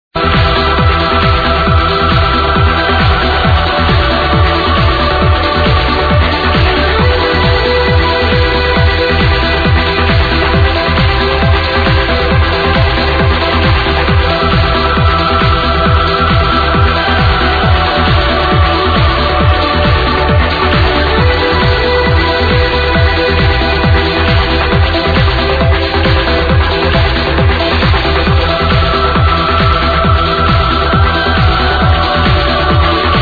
Great trance tune